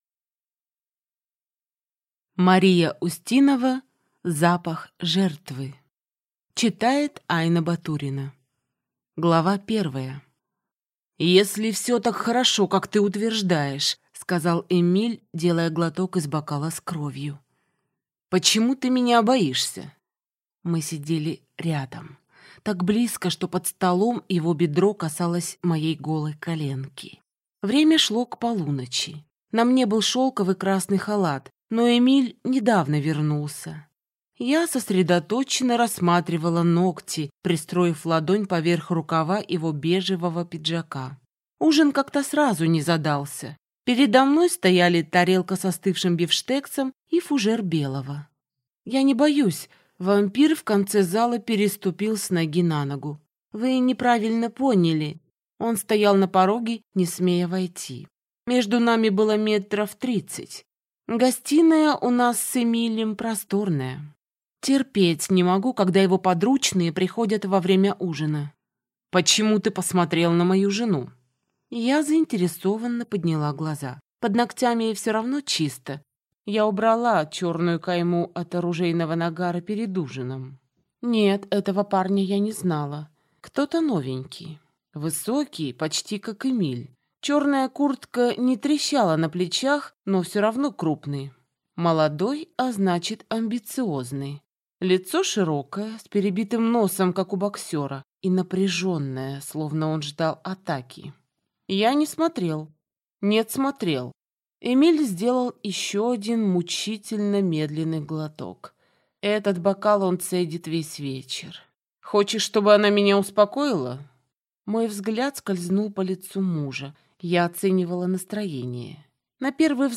Аудиокнига Запах жертвы | Библиотека аудиокниг